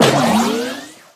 springboard_jump_01.ogg